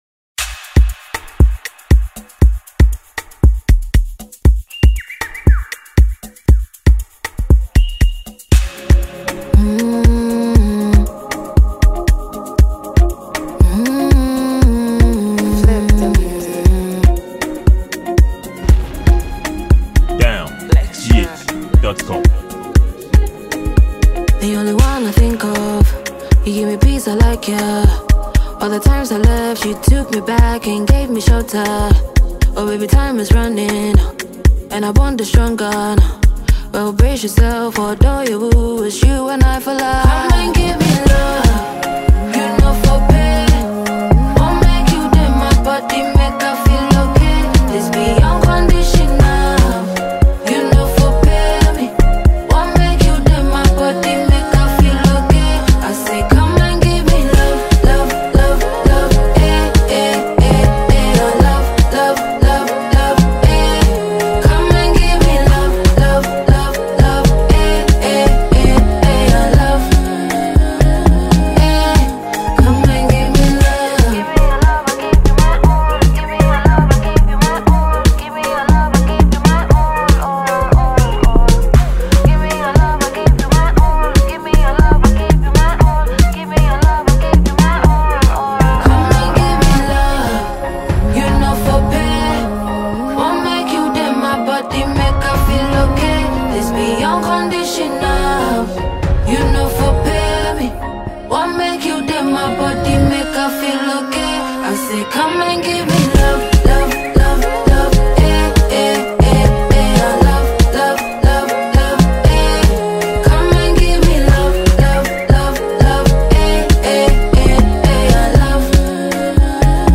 a Ghanaian afrobeat singer and songwriter